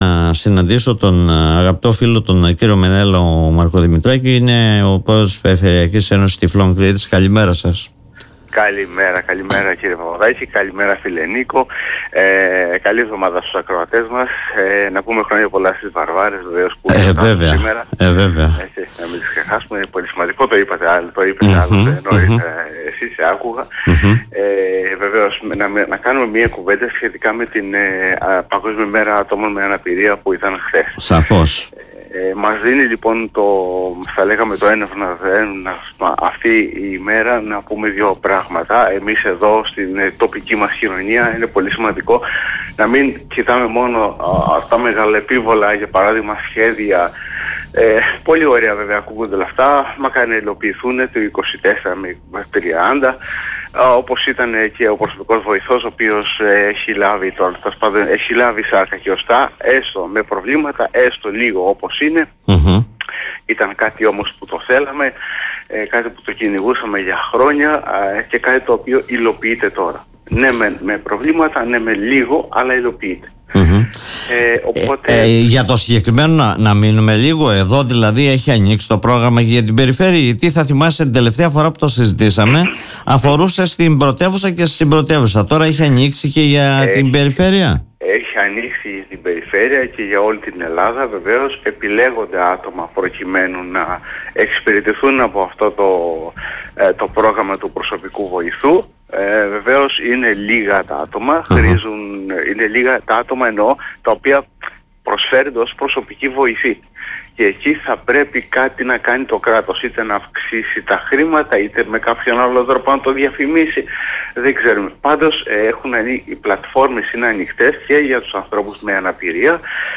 που φιλοξενήθηκε στην εκπομπή “Δημοσίως”